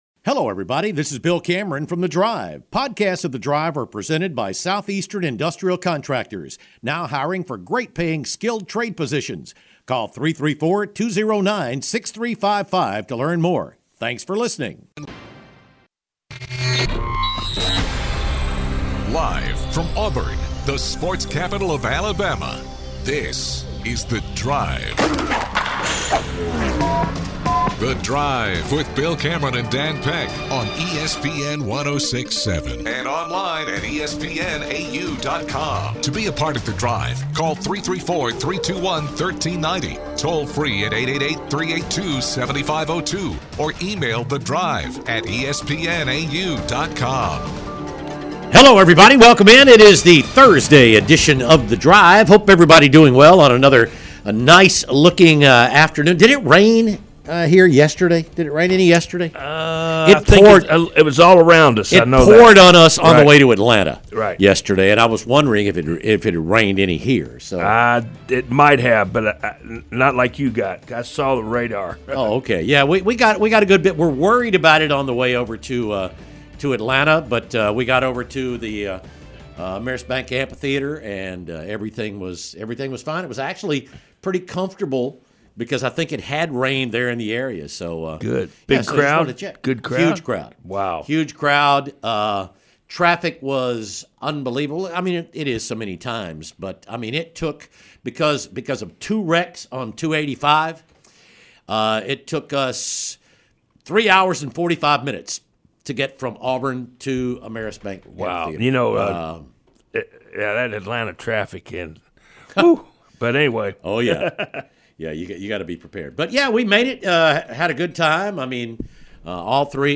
Live from SEC Media Days !